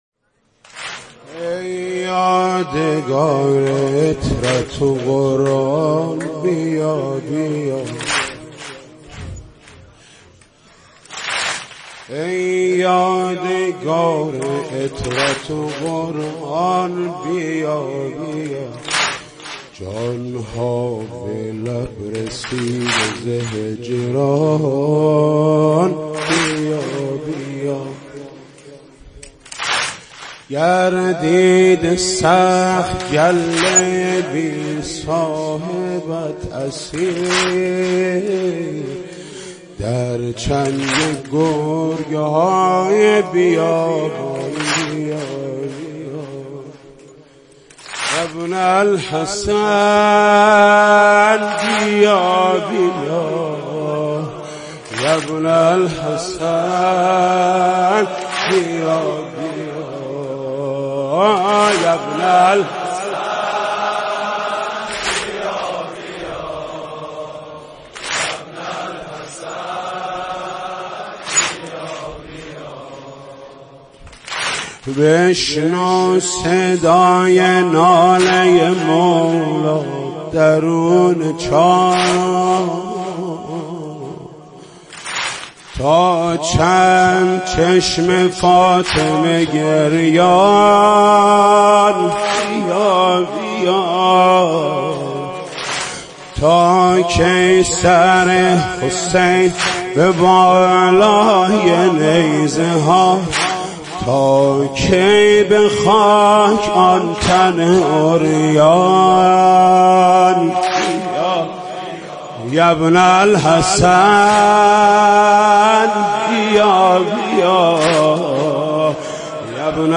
مناجات محرمی - امام زمان(عج) -( ای یادگــار عتــرت و قـرآن بیا بیا )